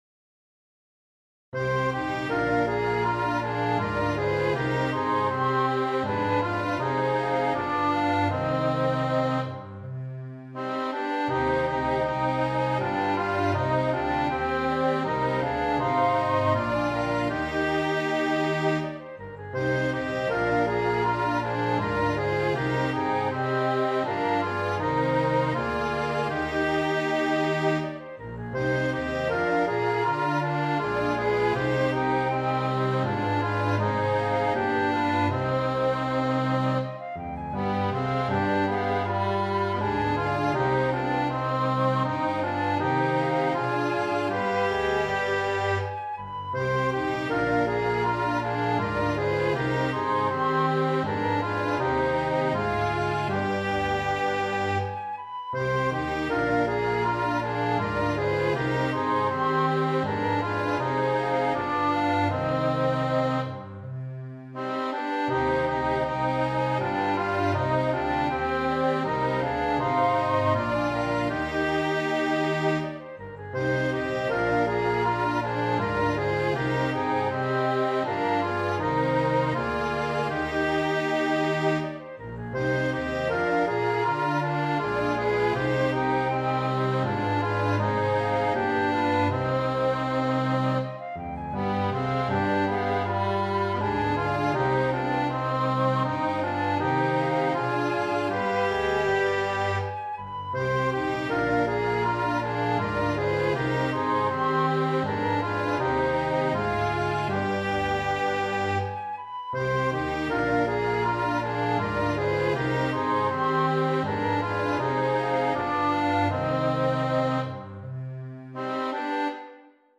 Efter mange år er vi gået i gang med at genoplive gruppen, som turnerede rundt i Europa, Asien og Nord Afrika med Jidisch musik – her i blandt denne melodi.
Harmonika/1.violin og 2.violin
Fløjte/piano
Kontrabas
lyd-Oyfn-pripetshik-i-C-mol.mp3